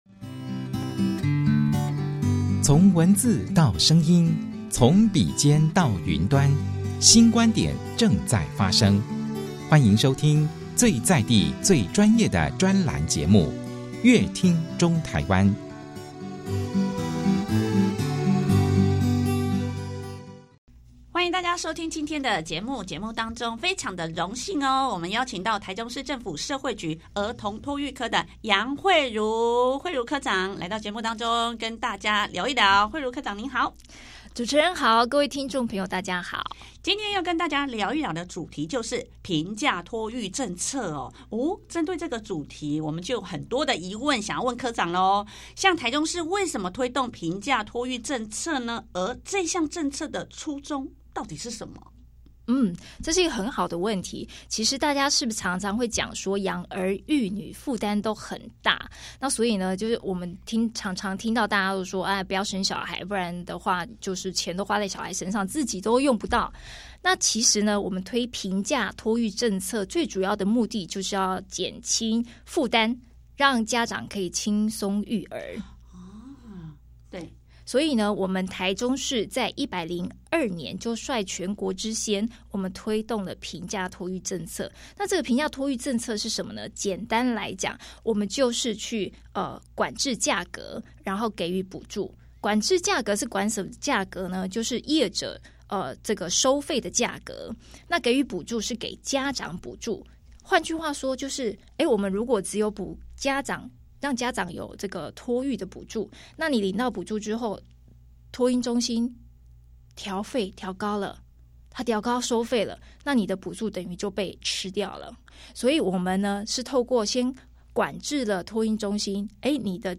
想知道更多精彩的訪談內容，請鎖定本集節目內容。